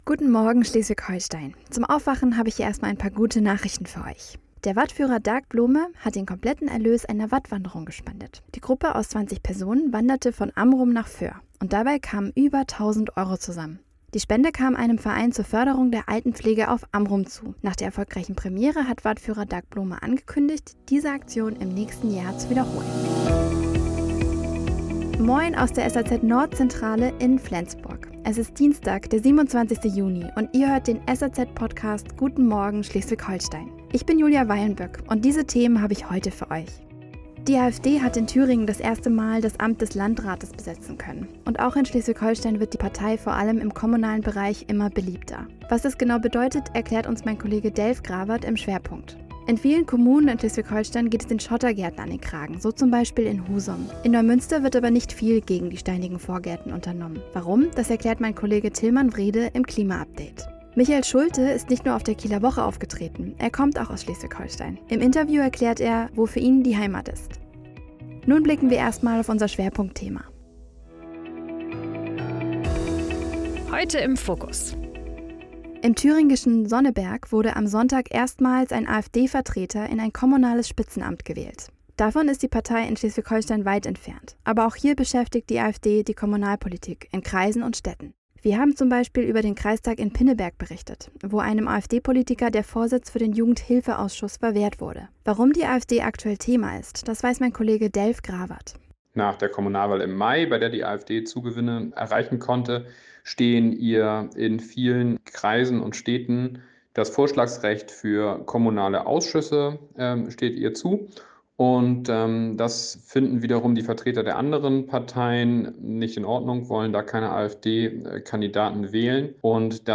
27.06. Die AfD in SH, Michael Schulte im Interview und Schottergärten in Neumünster ~ Guten Morgen SH Podcast